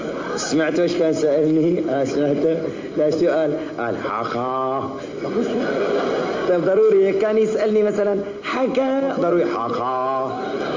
syrian_theatre.mp3